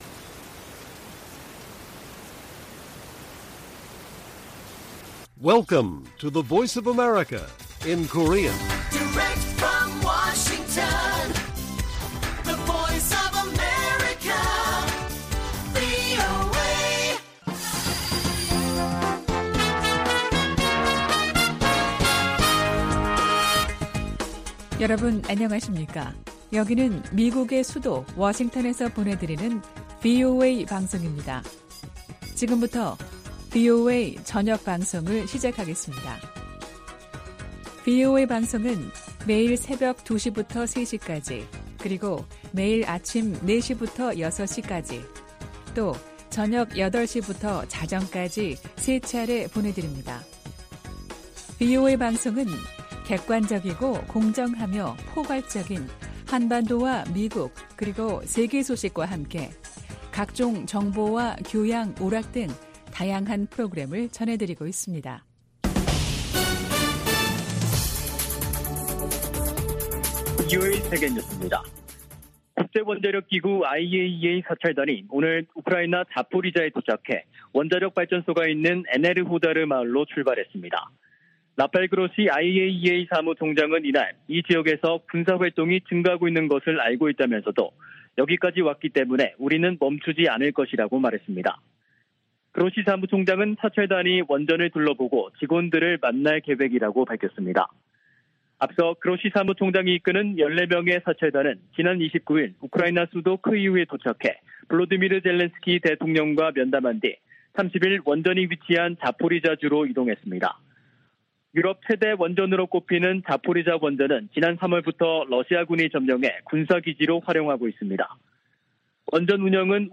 VOA 한국어 간판 뉴스 프로그램 '뉴스 투데이', 2022년 9월 1일 1부 방송입니다. 한국에서 실시된 미한 연합군사연습, 을지프리덤실드(UFS)가 1일 끝났습니다. 미 국방부 부장관이 북한을 미국이 직면한 중대한 위협 가운데 하나로 지목하고, 도전에 맞서기 위한 기술 혁신이 필수적이라고 강조했습니다. 1일 하와이에서 열리는 미한일 안보수장 회동에서는 북한의 추가 도발 가능성에 대한 공조 방안이 집중 논의될 것이라고 미 전직 관리들은 전망했습니다.